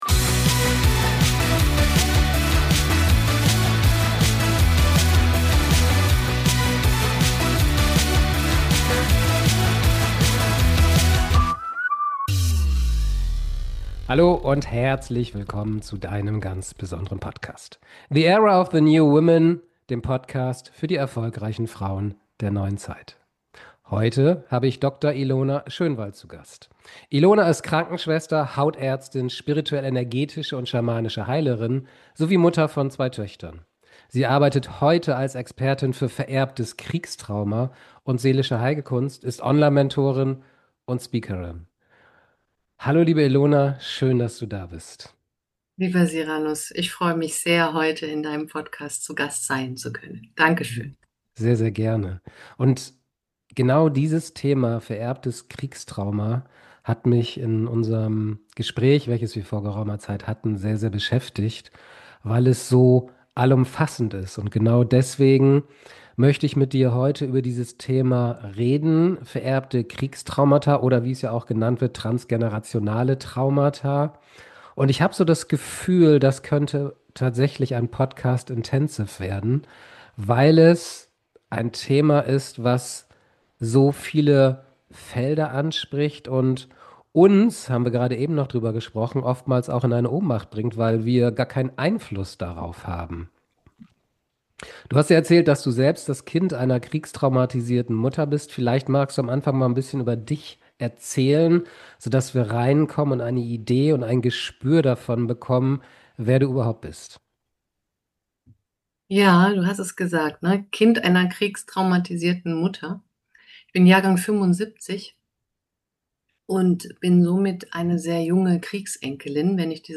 #056 In dieser Abhängigkeit stecken wir alle fest. INTENSIVE Interview